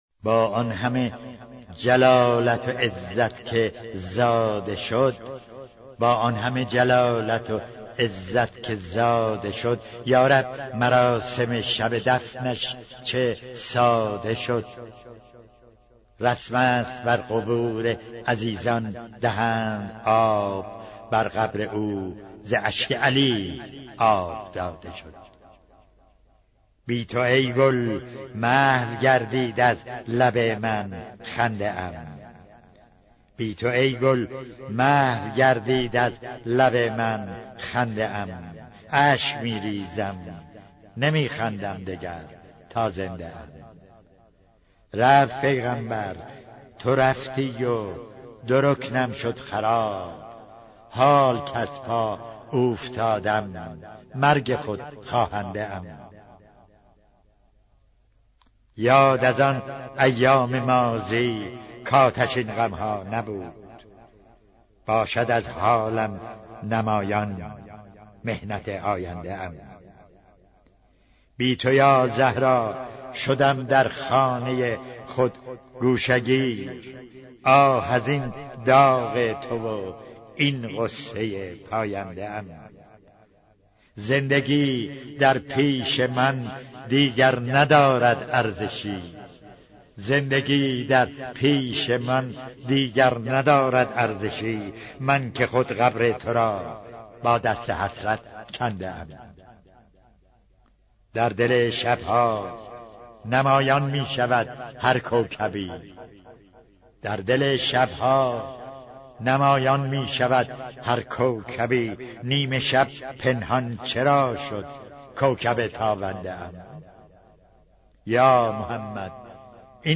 دانلود مداحی کوکب تابنده - دانلود ریمیکس و آهنگ جدید